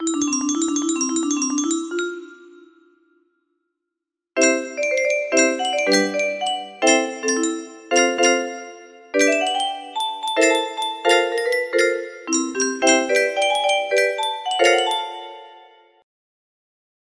10708 music box melody
Grand Illusions 30 (F scale)